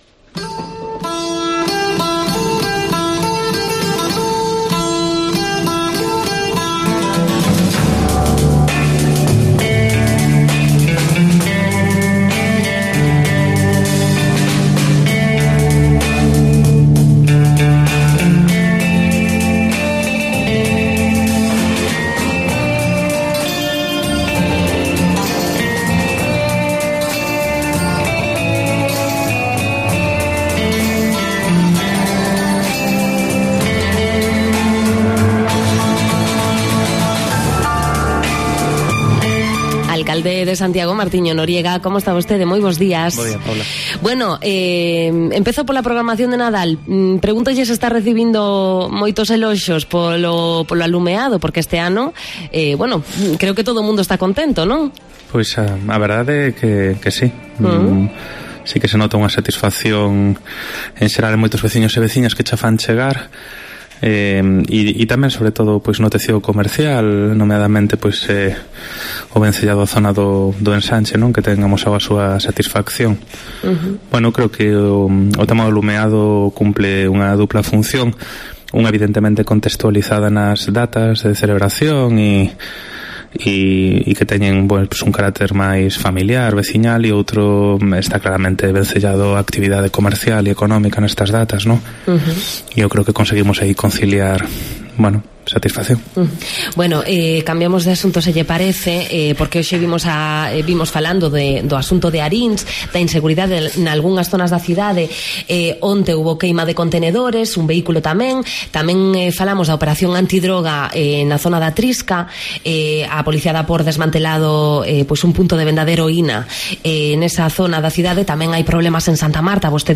Repaso a la actualidad de Santiago con Martiño Noriega en Cope: el regidor compostelano insistió en nuestros micrófonos en la inseguridad jurídica...